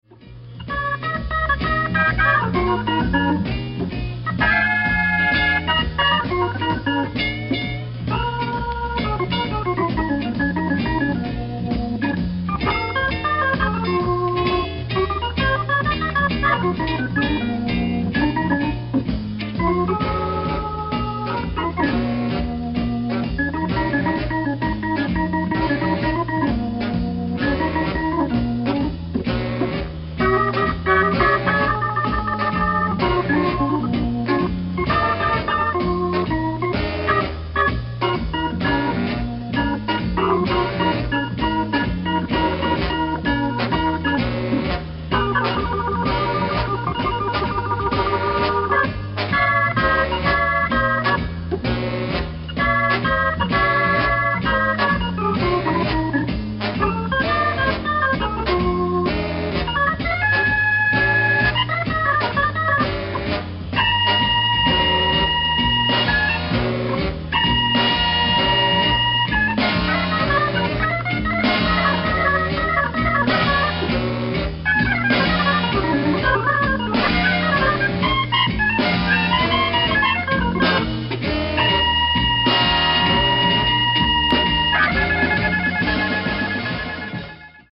Je trouve également une certaine mélancolie dans son jeu.